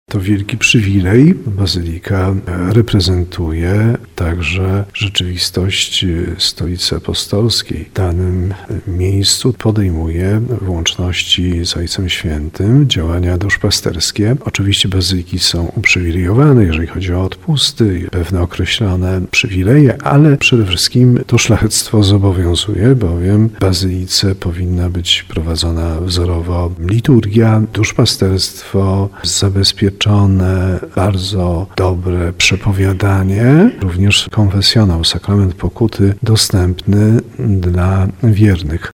Biskup tarnowski Andrzej Jeż mówi, że jest już decyzja Watykanu o nadaniu tytułu bazyliki mniejszej kościołowi w Dąbrowie Tarnowskiej.